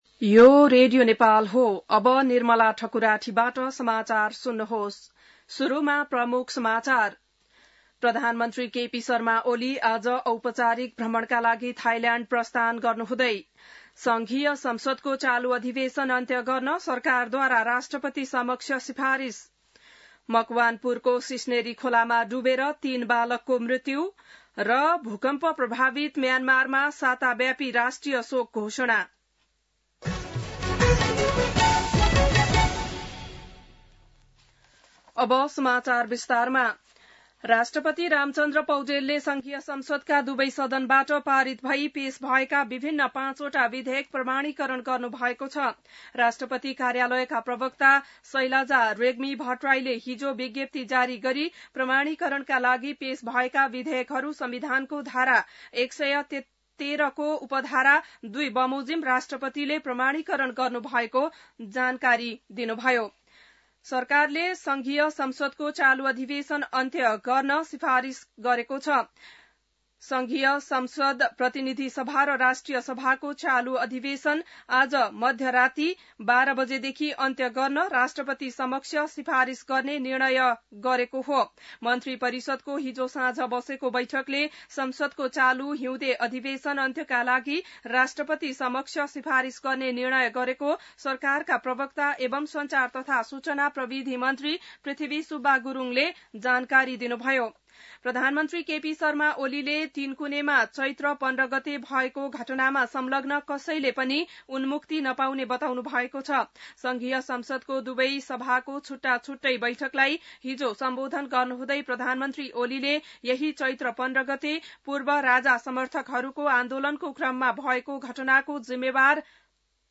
बिहान ९ बजेको नेपाली समाचार : १९ चैत , २०८१